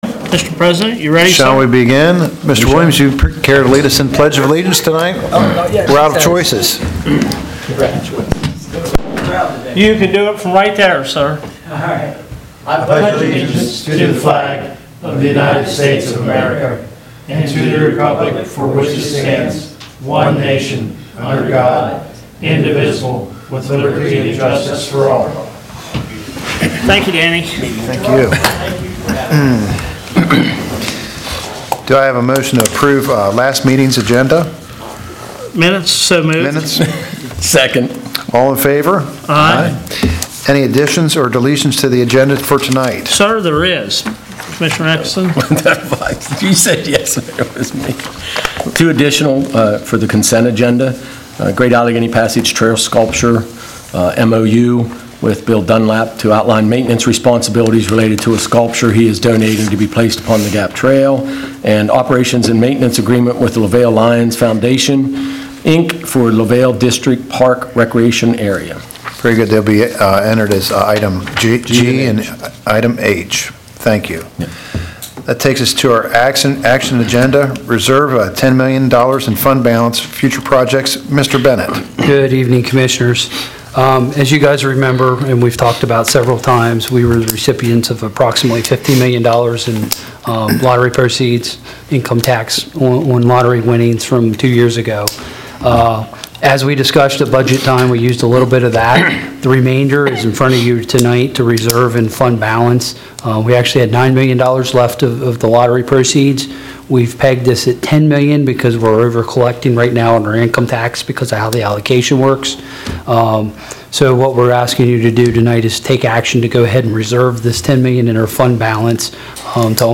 BOCC-Public-Business-Meeting---June-8-2023